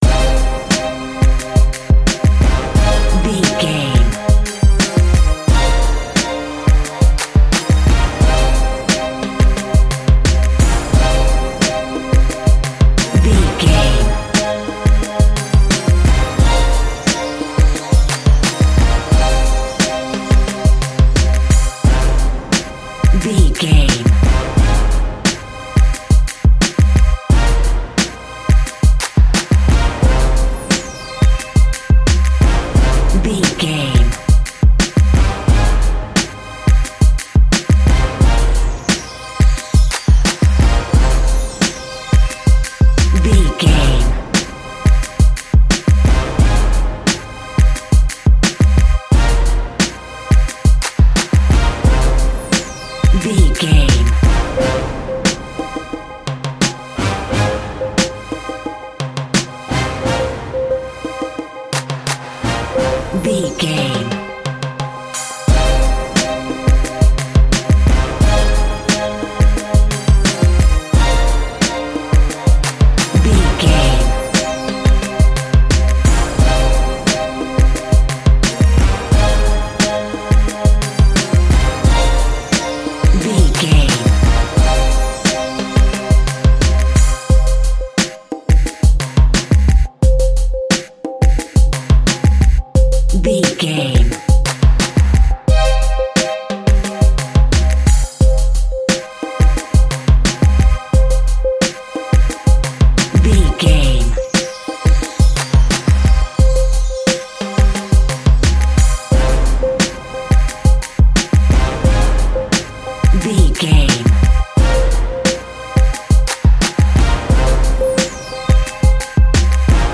Aeolian/Minor
strings
drums
drum machine
synthesiser
brass
funky